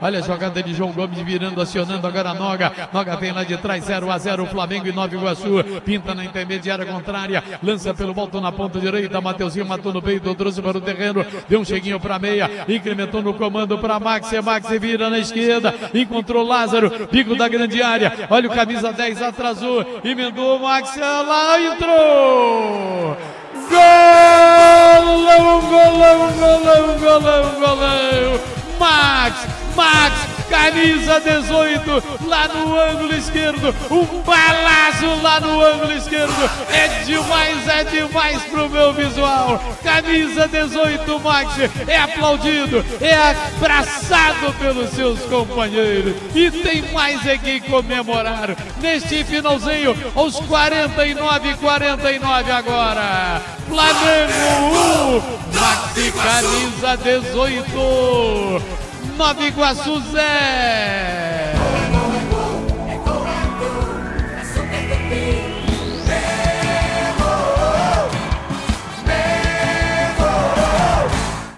Ouça o gol da vitória do Flamengo sobre o Nova Iguaçu com a narração de José Carlos Araújo